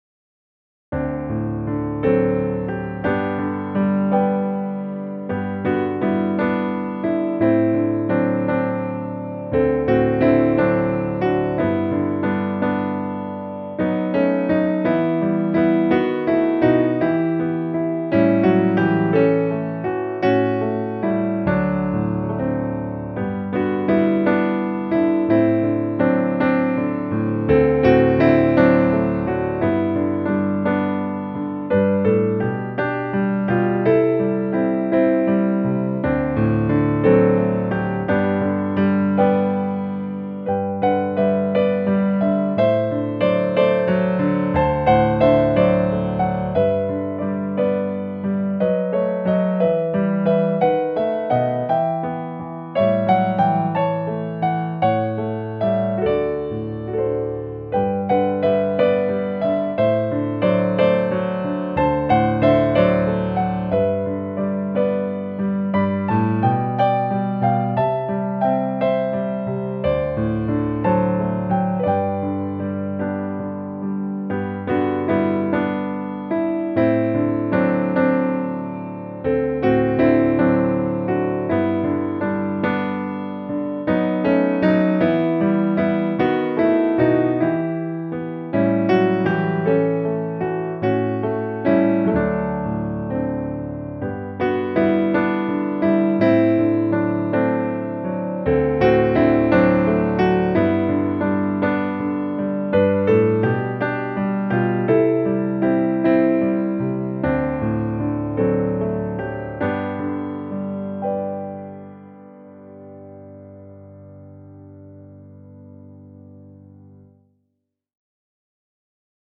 Here’s a great hymn to sing before Bible study.